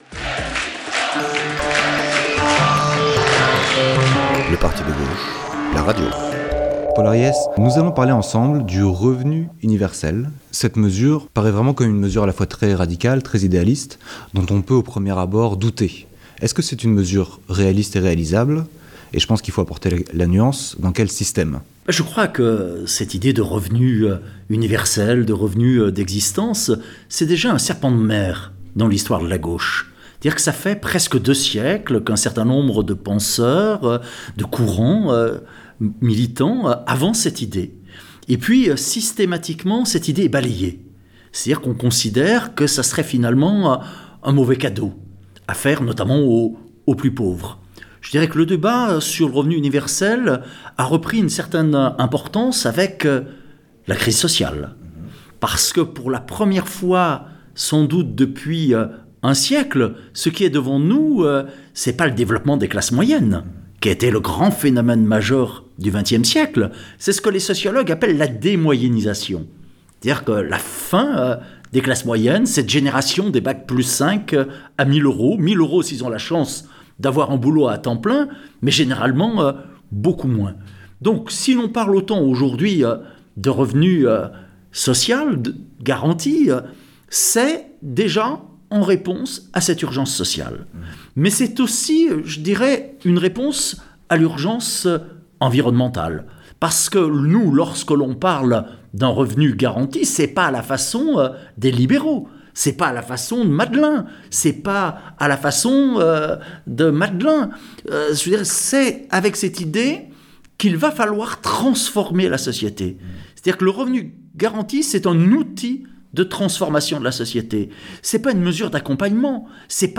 Un débat avec Bernard Friot et Paul Ariès
Ci-dessous le son de l’atelier compressé pour une meilleure écoute .. et des applaudissements qui n’éclatent pas les oreilles .. : Revenu de base, salaire universel ou sécurité sociale professionnelle ?